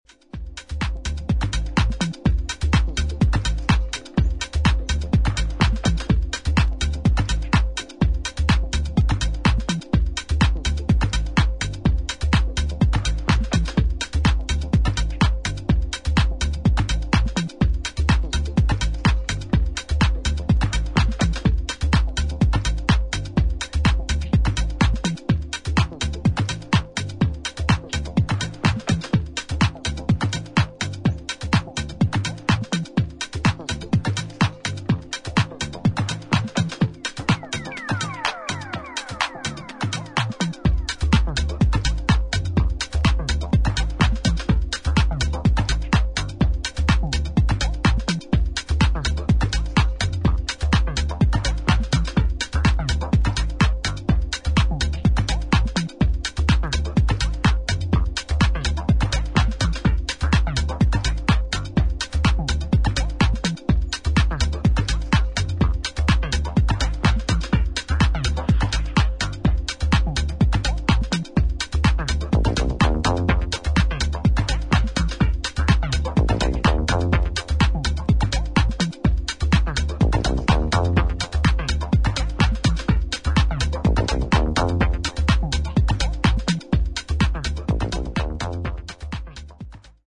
グルーヴを丁寧に刻んでいくテックハウス全4曲を収録